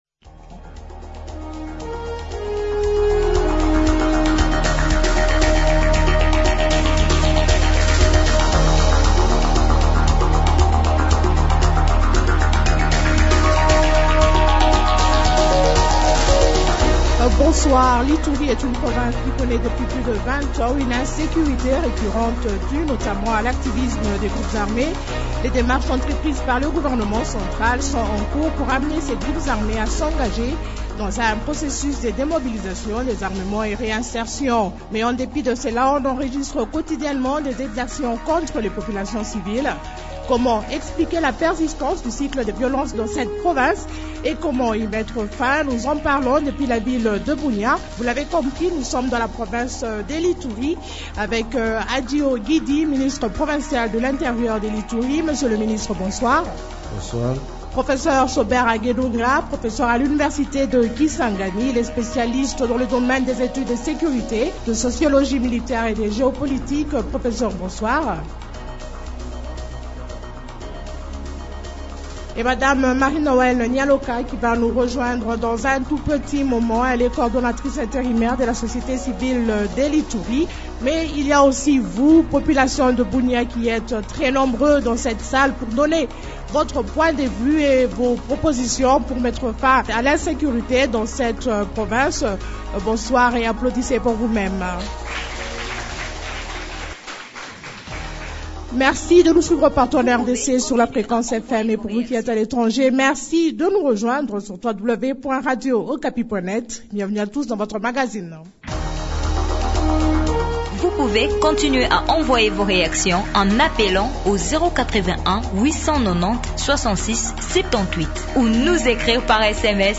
Spécial Dialogue entre Congolais à Bunia : persistante du cycle de violence dans la Province de l’Ituri